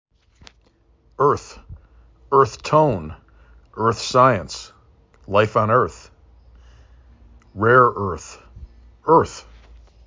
5 Letters , 1 Syllable
er T